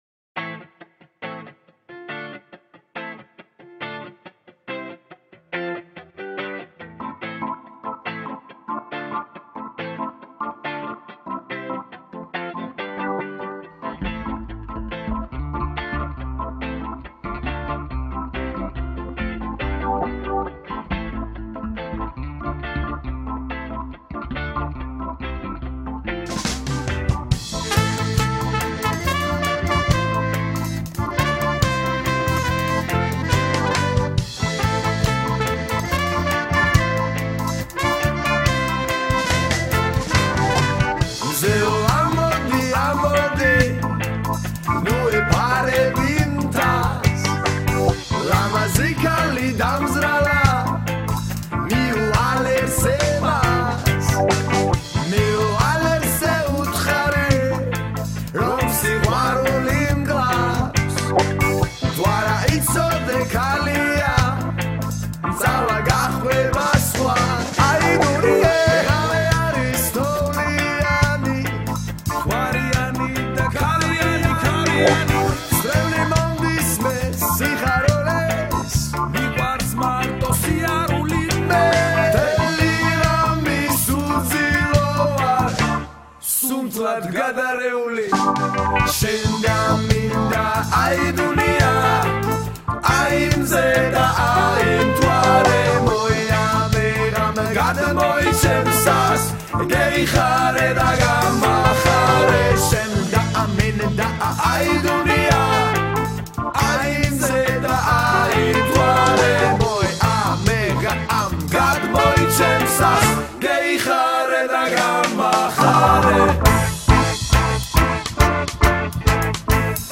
კატეგორია: Reggae